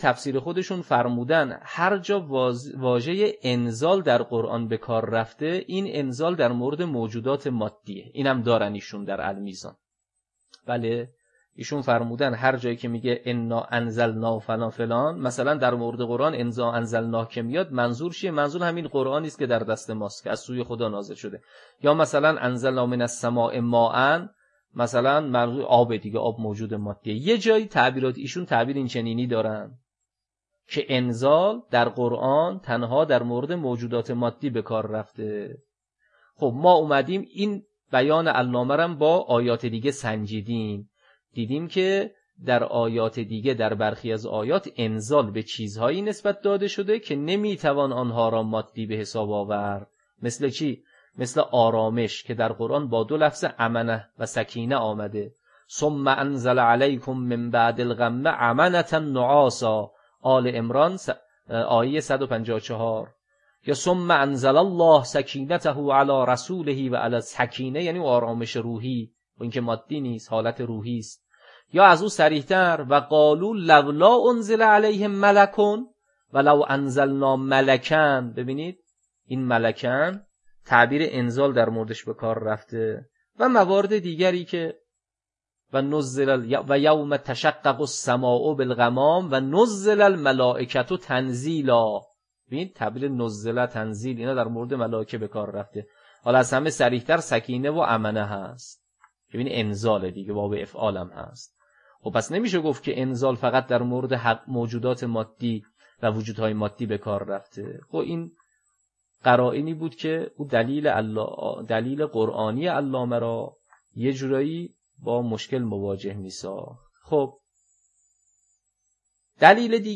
تدریس کلام تطبیقی